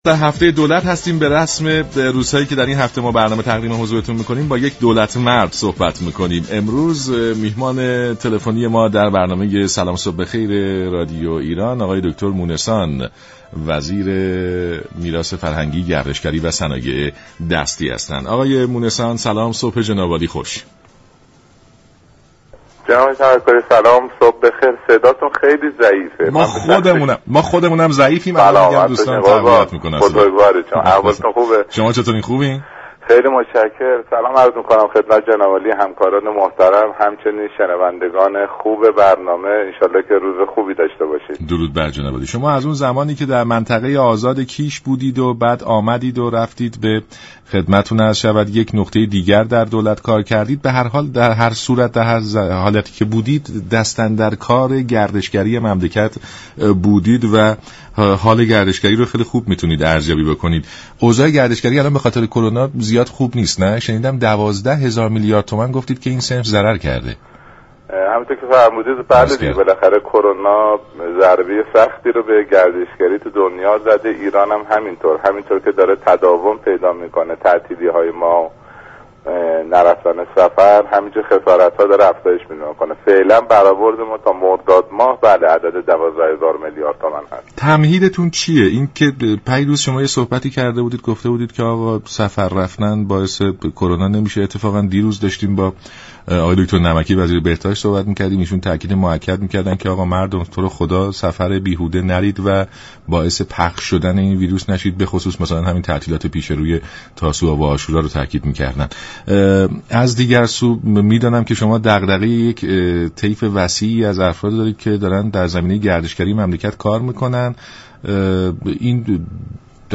به گزارش شبكه رادیویی ایران، علی اصغر مونسان وزیر میراث فرهنگی، گردشگری و صنایع دستی در برنامه سلام صبح بخیر به وضعیت گردشگری ایران پس از شیوع ویروس كرونا پرداخت و گفت: صنعت گردشگری ایران این روزها تحت تاثیر ویروس كرونا قرار گرفته و تا مرداد سال جاری حدود 12 هزار میلیارد تومان خسارت دیده است وی در ادامه در پاسخ به این پرسش كه آیا سفر رفتن موجب شیوع بیشتر این بیماری می شود؟